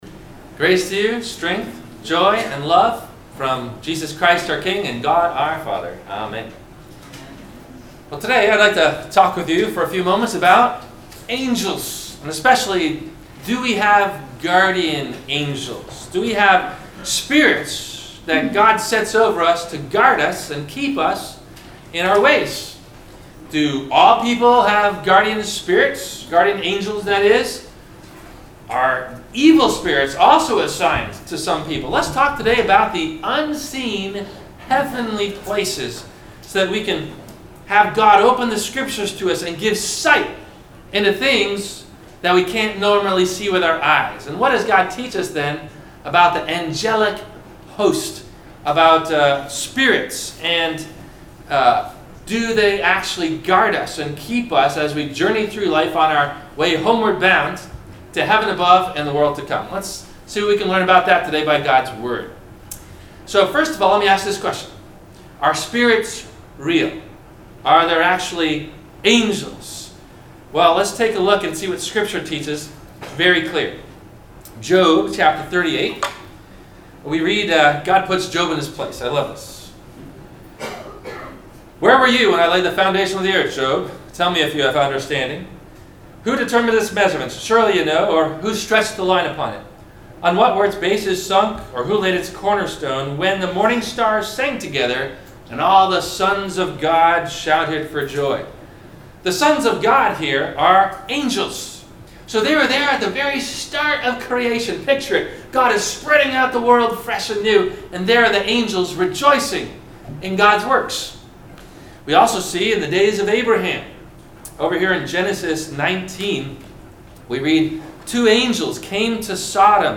- Sermon - February 25 2018 - Christ Lutheran Cape Canaveral